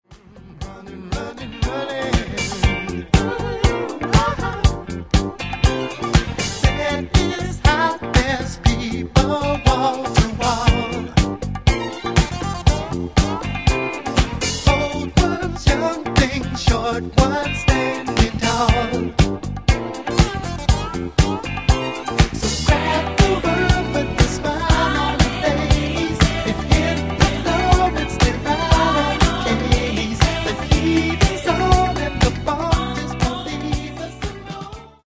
Genere:   Disco Funk